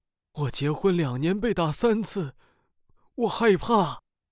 Chinese_Mandarin_Multi-emotional_Synthesis_Corpus